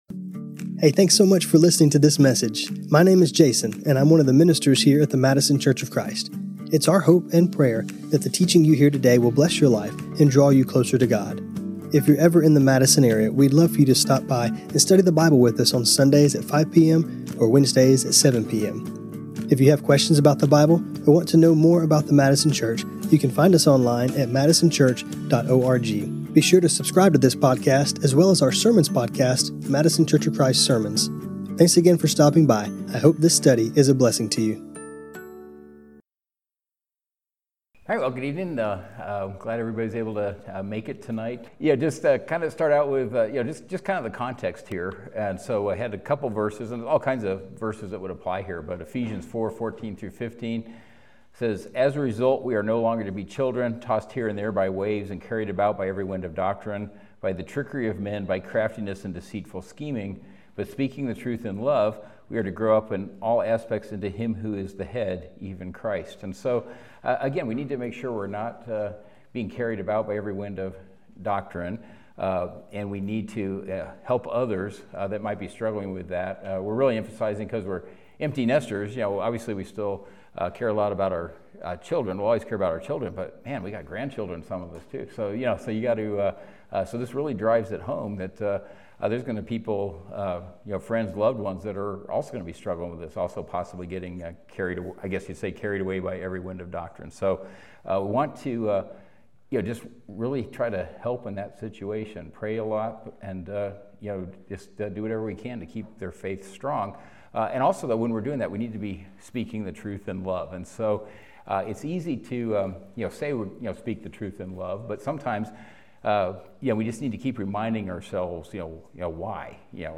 Empty Nester Bible Study